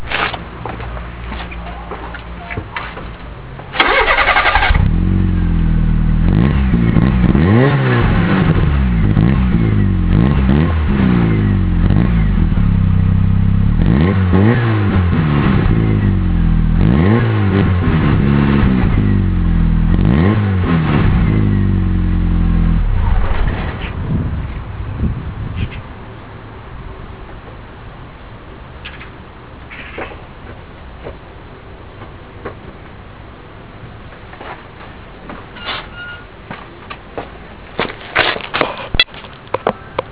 Nagrałem sobie mój wydech:
Bez silencera: